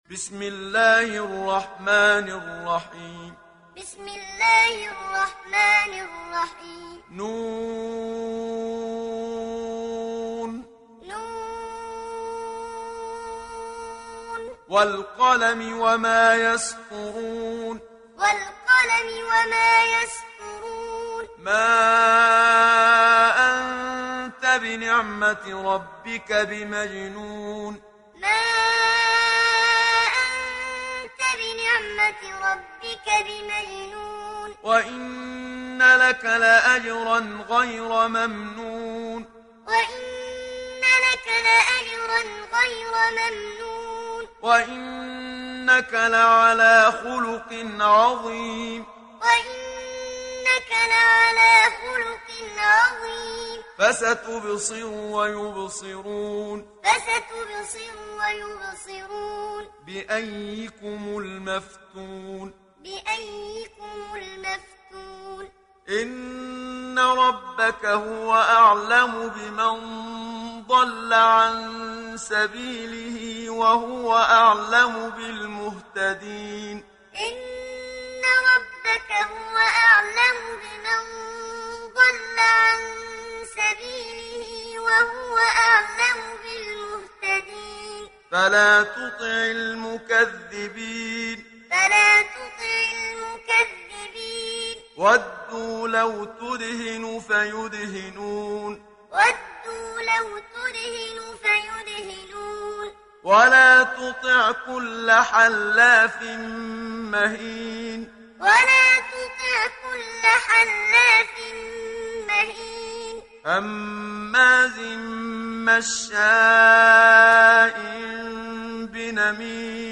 دانلود سوره القلم mp3 محمد صديق المنشاوي معلم روایت حفص از عاصم, قرآن را دانلود کنید و گوش کن mp3 ، لینک مستقیم کامل
دانلود سوره القلم محمد صديق المنشاوي معلم